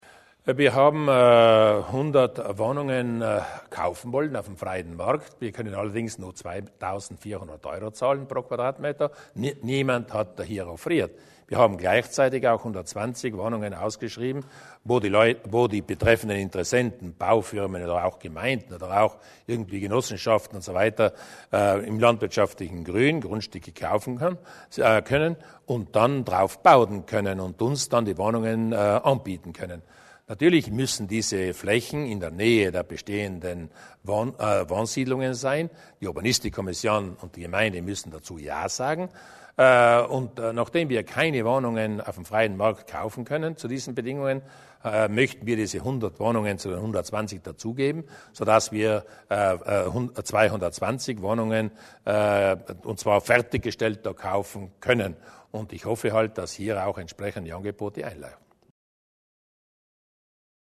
Landeshauptmann Durnwalder zu den Wohnungen für den Mittelstand